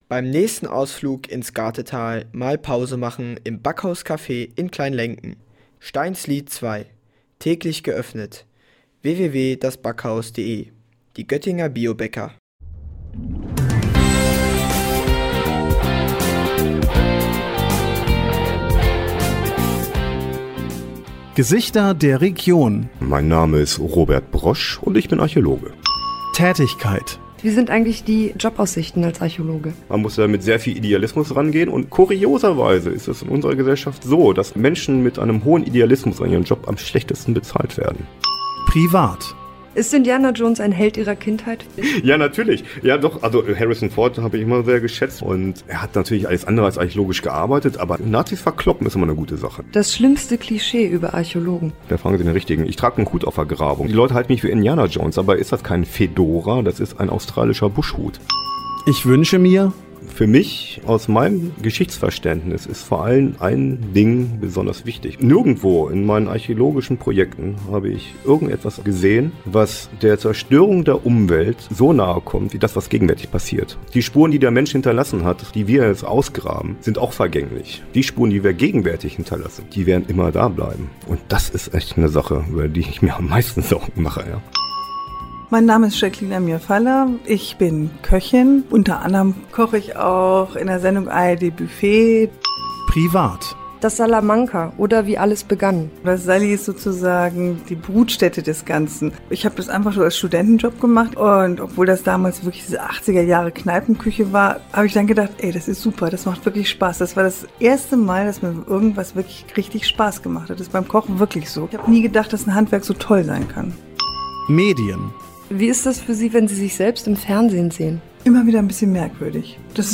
Hören Sie jetzt den zweiten Teil unseres „Best of“ von „Gesichter der Region“. Diesmal mit zwei Historikern mit einer etwas anderen Perspektive auf die Welt.